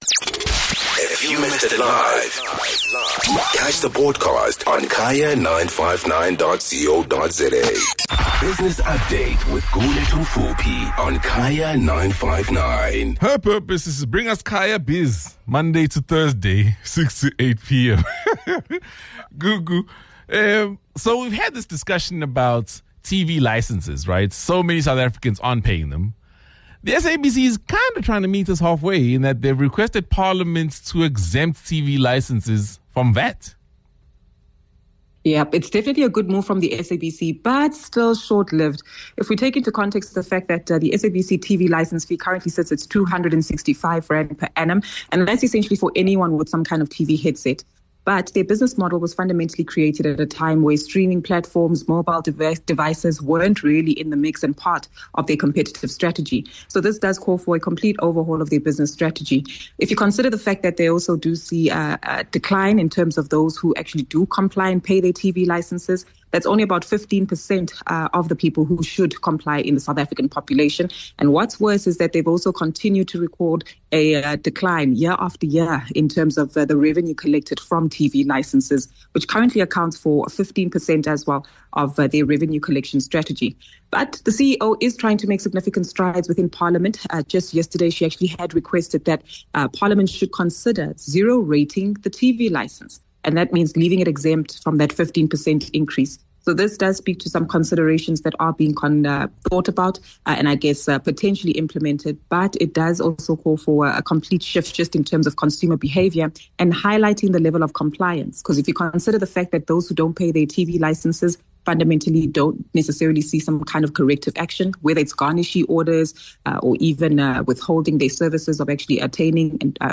22 Jul Business Update: SABC wants VAT exemption on TV licences